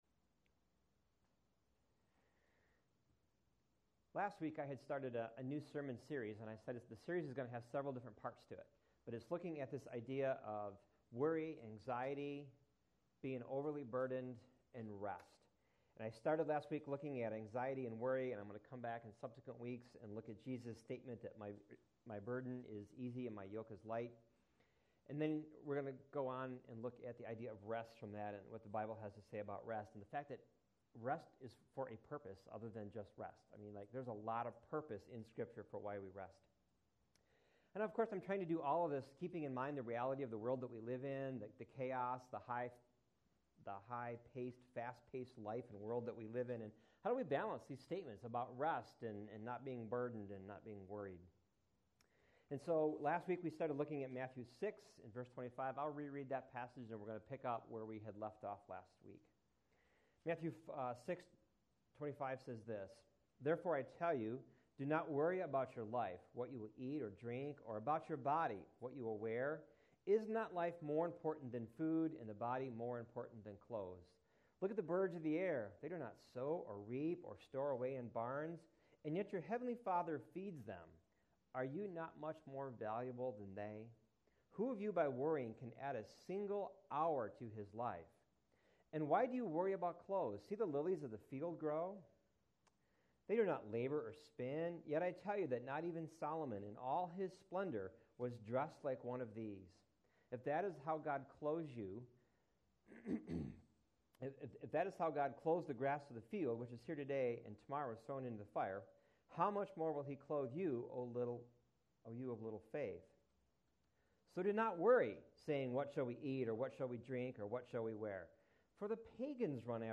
October 19, 2014 October 19, 2014 by admin in Media , Sermons 0 Do Not Worry…Consider Closely Part II Previous Post Link Do Not Worry: Consider Closely….